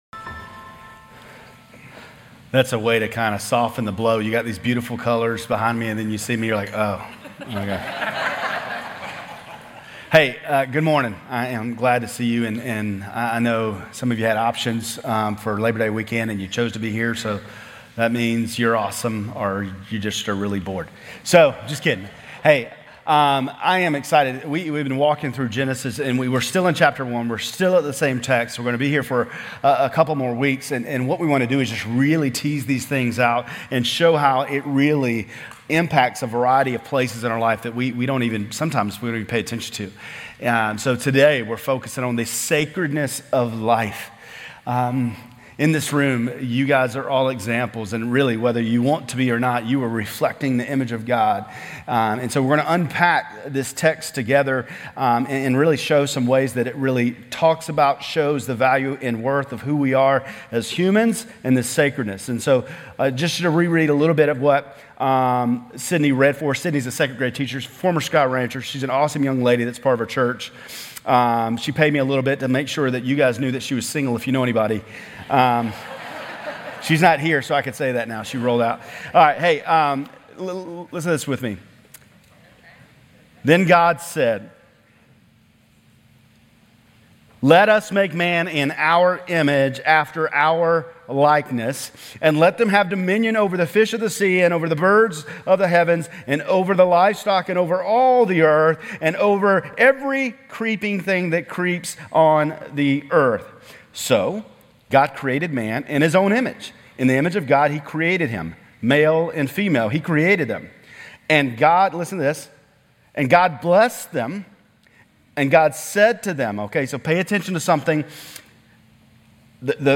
Grace Community Church Lindale Campus Sermons Genesis 1:26-27 - Sanctity of Life Sep 02 2024 | 00:33:40 1x 00:00 / 00:33:40 Subscribe Share RSS Feed Share Link Embed